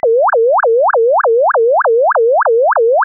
(Fig. 5g): NOTAP result using Zero Order Hold interpolation, an oversampling ratio, N = 500, and the receiver sampling frequency of 4 kHz.